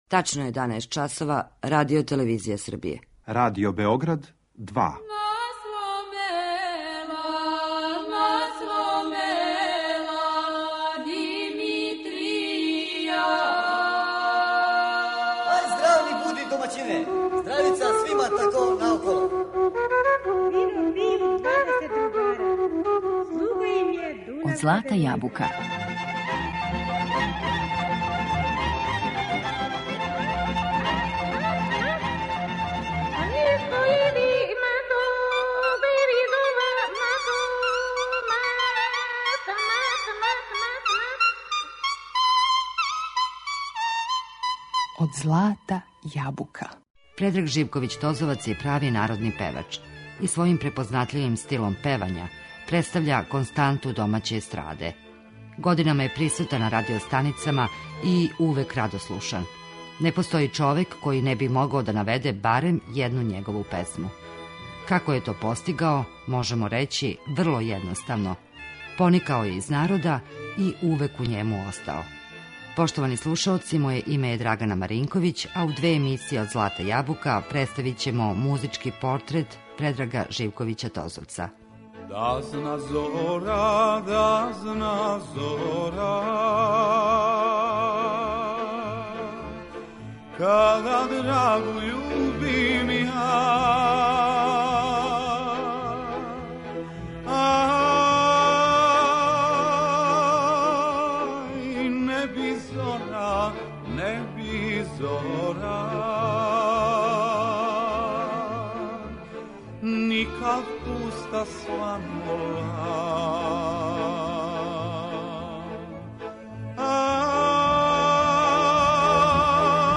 У две емисије представићемо музички портрет уметника, хармоникаша, композитора и солисте Радио Београда, Предрага Живковића Тозовца.